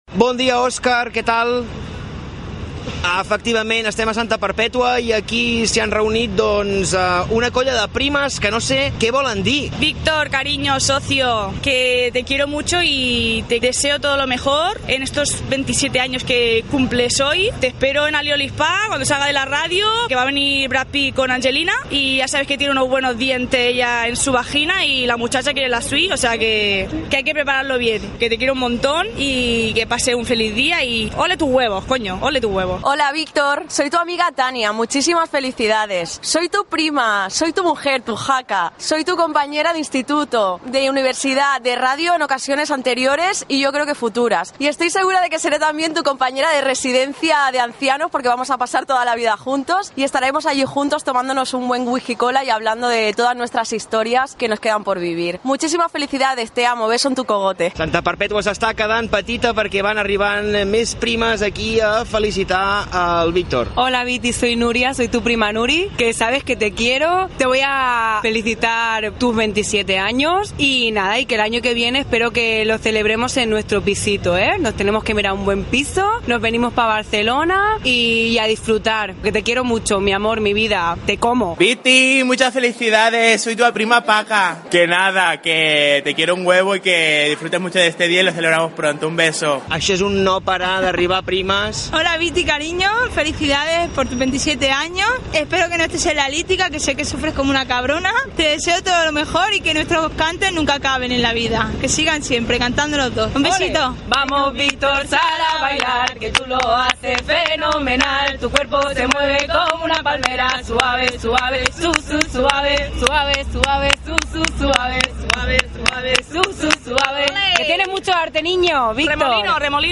Connexió amb Santa Perpètua de Mogoda.
Entreteniment